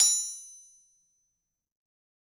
Anvil_Hit1_v3_Sum.wav